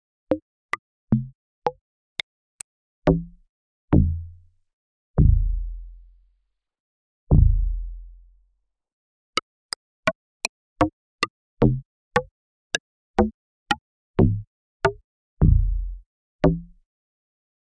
click plink pop boop bonk
bass bonk boop click hit hollow plink pop sound effect free sound royalty free Sound Effects